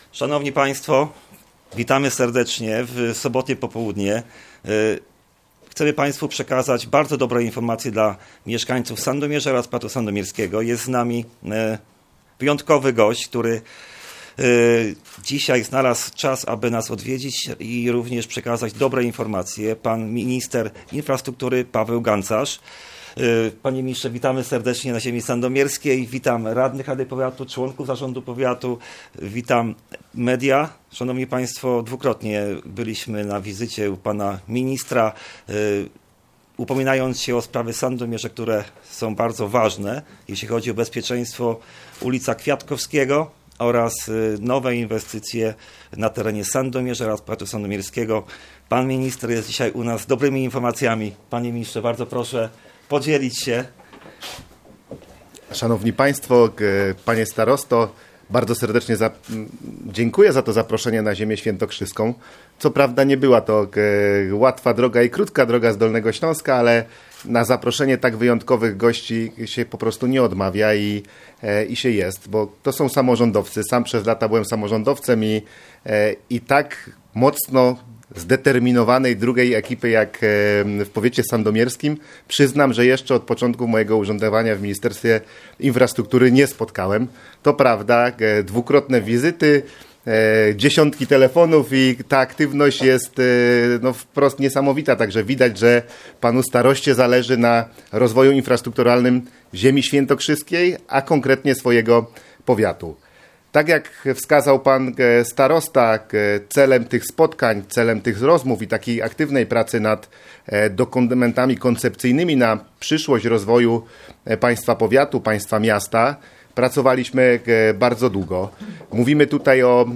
Budowa zachodniej obwodnicy Sandomierza od strony gminy Obrazów oraz przebudowa ulicy Kwiatkowskiego w Sandomierzu (w tym budowa dwóch rond i chodników) to plany, jakie zaprezentował goszczący w tym mieście wiceminister infrastruktury Paweł Gancarz wraz ze starostą sandomierskim Marcinem Piwnikiem: